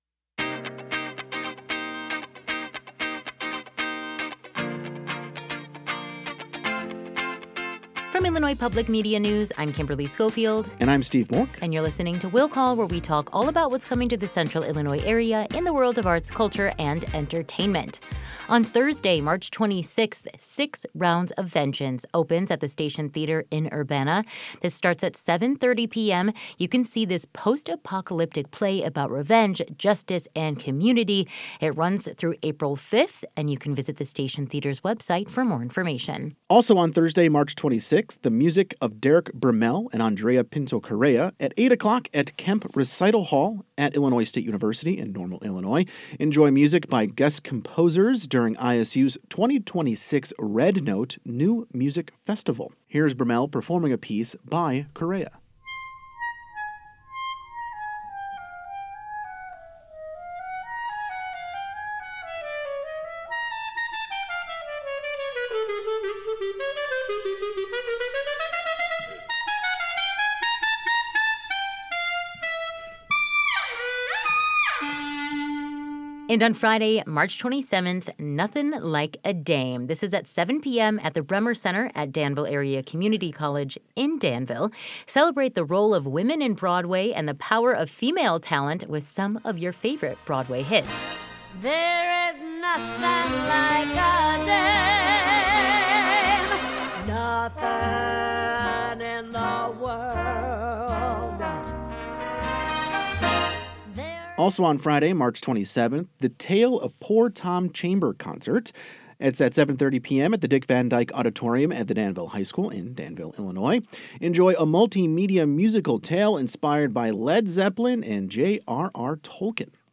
talk about weekend events